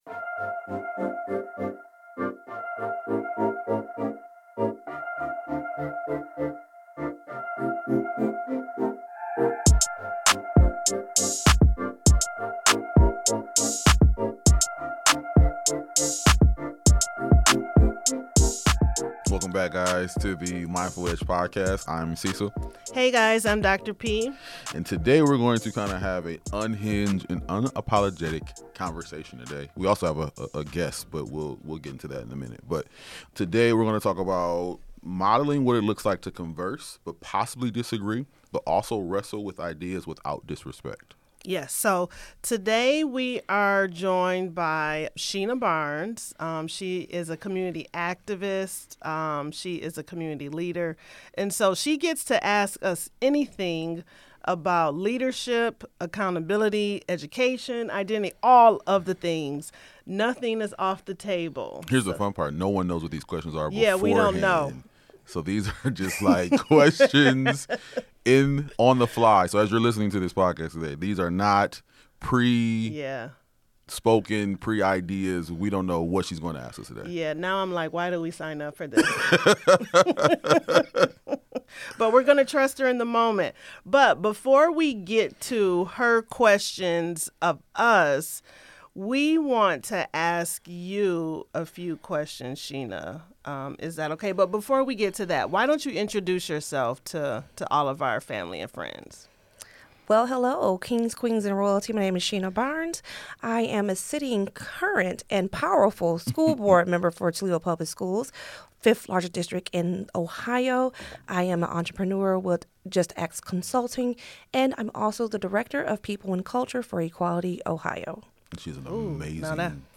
In this episode of The Mindful Edge, school board member Sheena Barnes joins us for an unscripted “Ask Us Anything” conversation. From leadership under pressure to protecting students in polarized times, nothing is off the table.